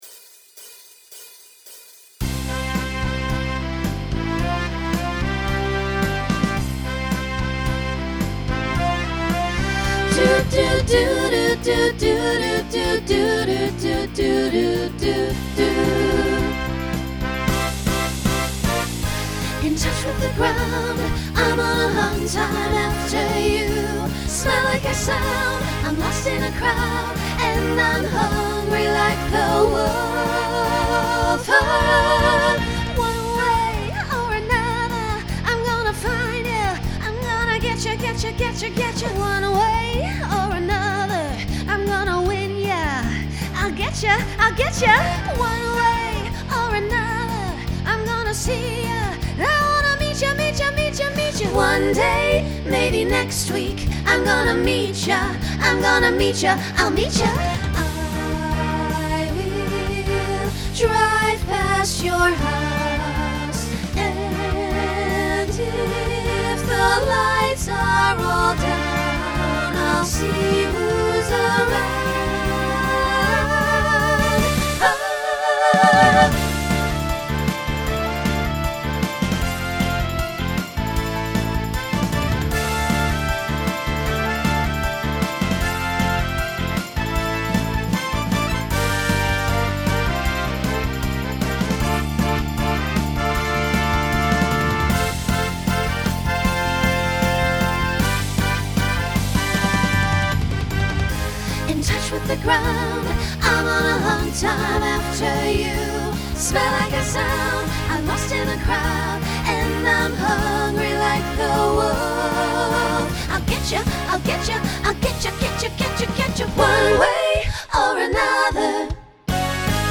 Genre Rock Instrumental combo
Transition Voicing SSA